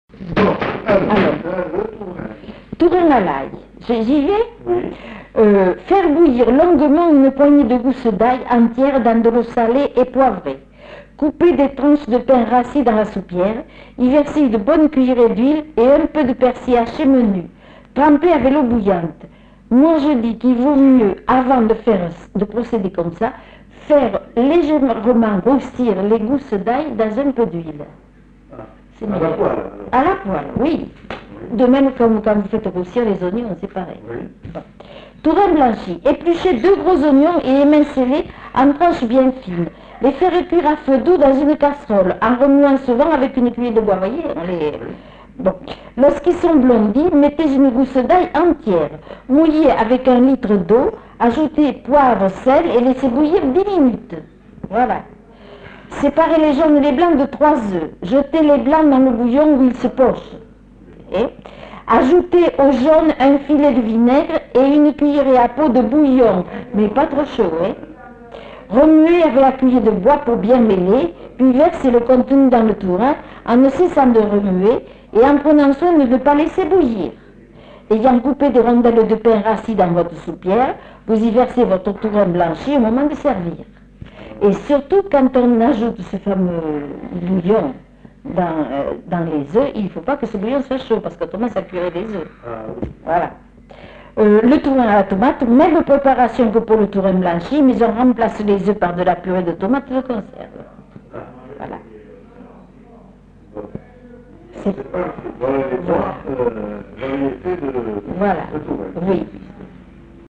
Aire culturelle : Bazadais
Lieu : La Réole
Genre : témoignage thématique